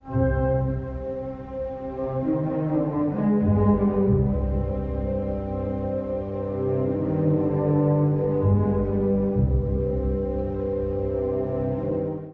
やがて第一主題が回想のように現れ、最後はC音のピチカートが3回、静かに鳴って消えるという印象的な終わり方。
全体を通じて総休止が効果的に使われており、音の「沈黙」までもが劇的な効果を持っています。